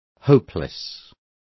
Also find out how nulas is pronounced correctly.